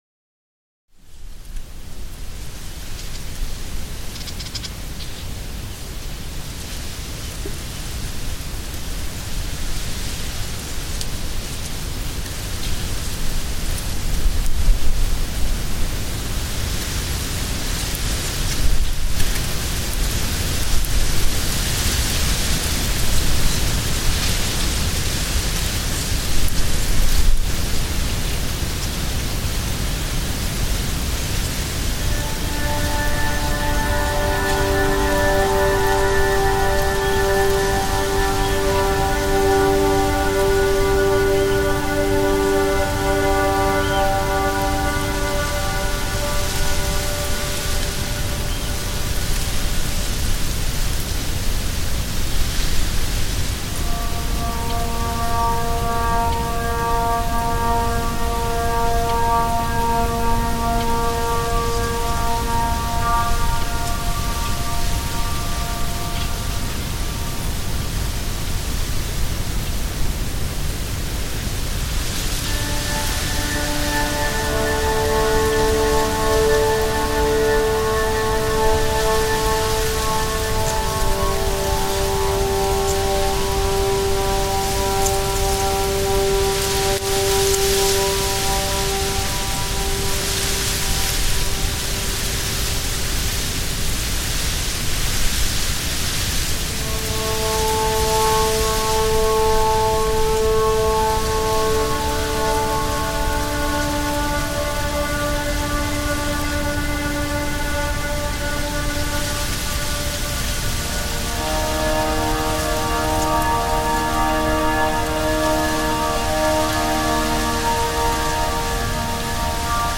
Delta wind in reeds on the beach
The quiet sounds of wind, rustling reeds, and seabirds sit in memory alongside the sound of waves.